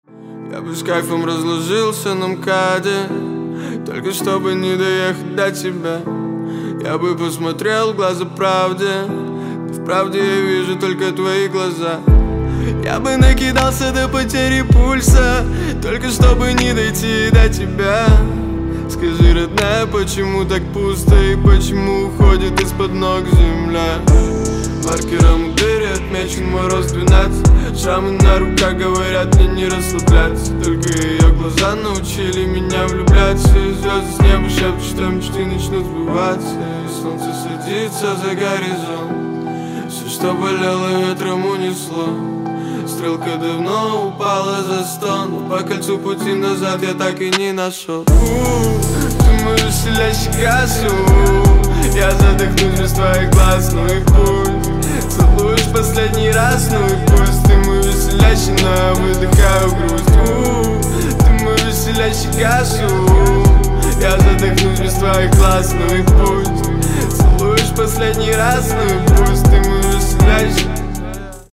мужской вокал
лирика
грустные
русский рэп
спокойные
клавишные
романтичные